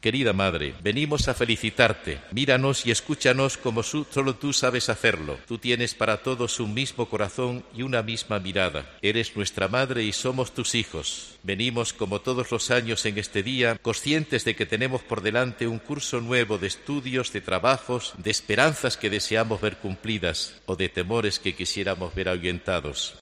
Ciriaco Benavente, obispo de Albacete
catedral de Albacete en el dia de la patrona
Pueden escucharse tres fragmentos de la homilia en la Eucaristía celebrada con motiivo de la festividad de la Virgen de los Llanos, patrona de Albacete, a la que han asistido cientos de albaceteños, así como representantes de todas las instituciones civiles y militares de Albacete.